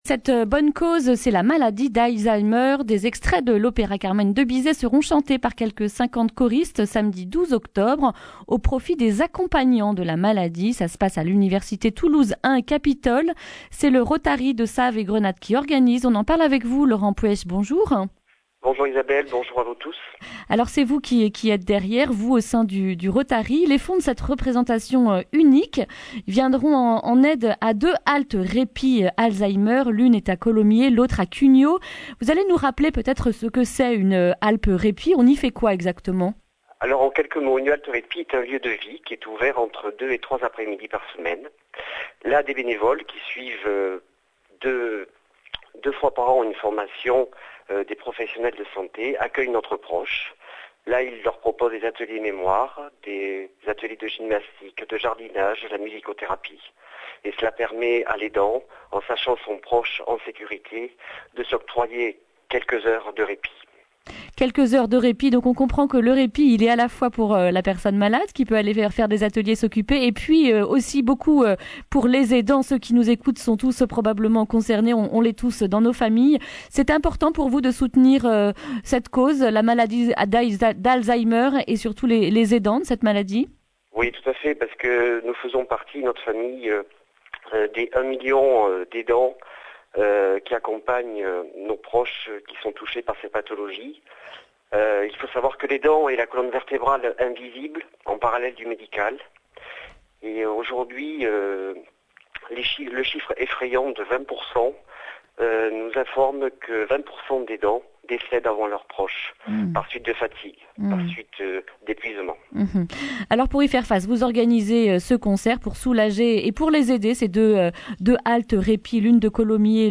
jeudi 10 octobre 2019 Le grand entretien Durée 10 min